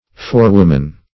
Forewoman \Fore"wom`an\, n.; pl. Forewomen.